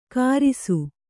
♪ kārisu